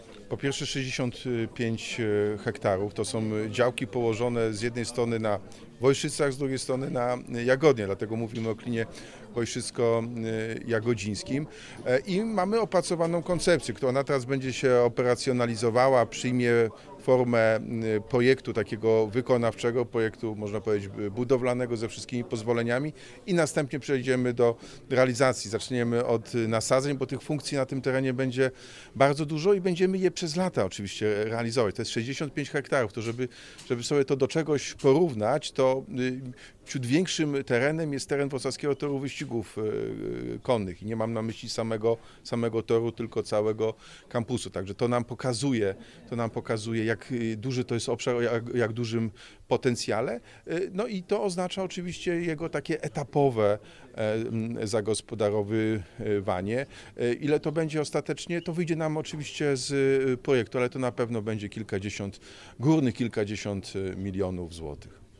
O lokalizacji działki mówi Jacek Sutryk, Prezydent Wrocławia.